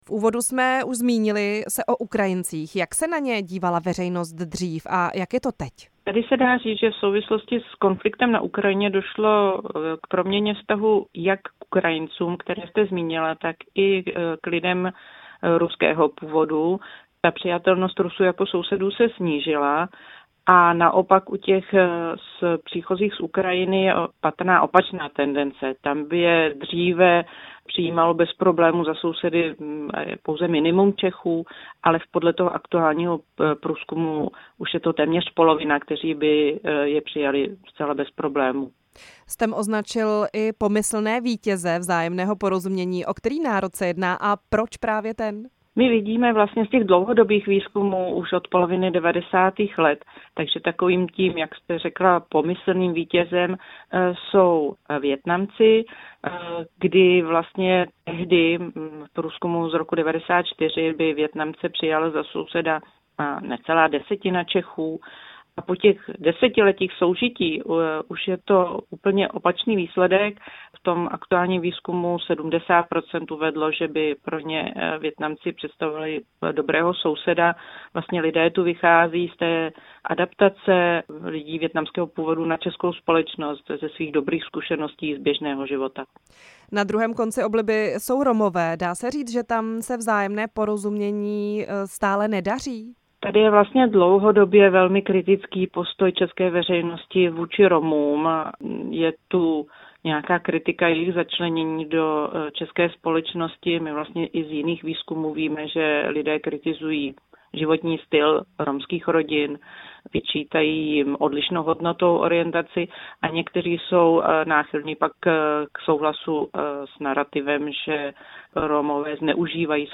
Moderátorka